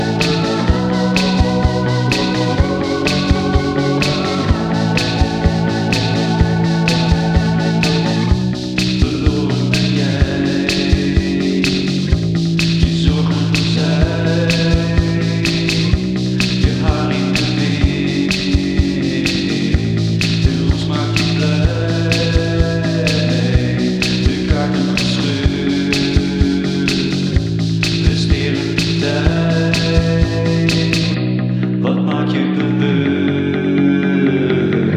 Жанр: Альтернатива / Электроника